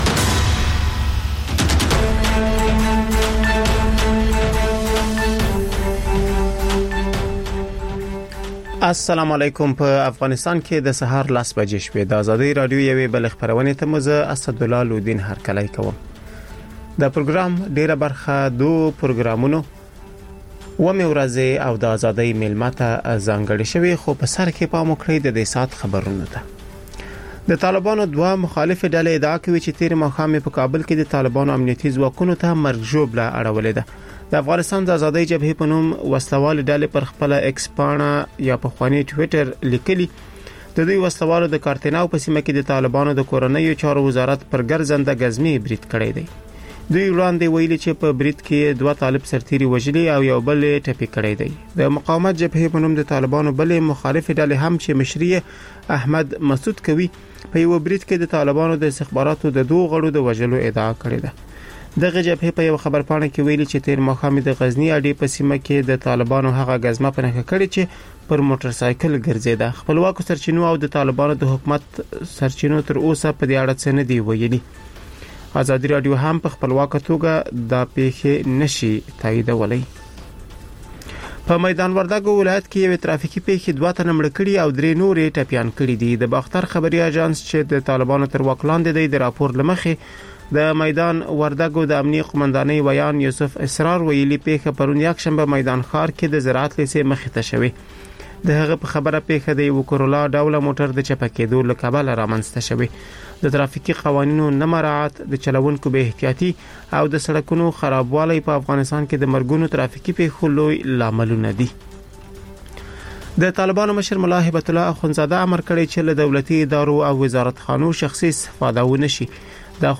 لنډ خبرونه